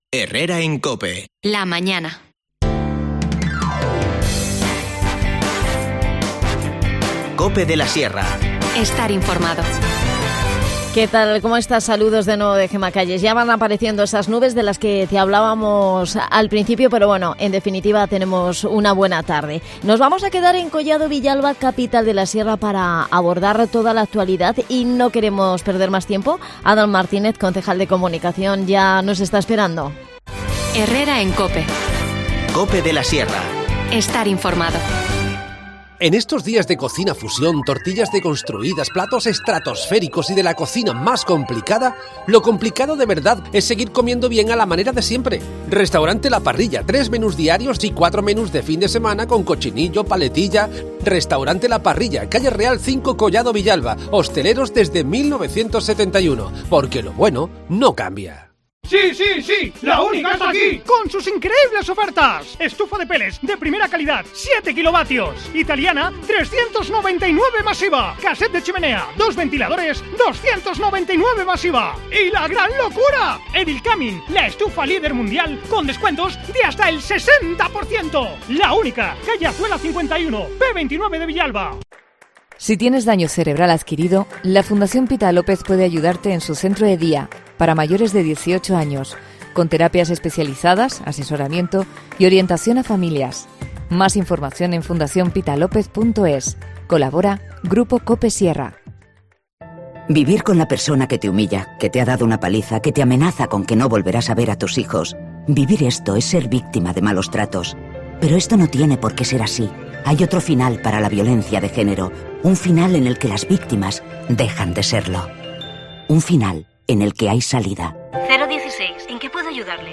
AUDIO: Abordamos la actualidad de Collado Villalba con Adan Martínez, concejal de Comunicación que nos habla sobre el rechazo de la...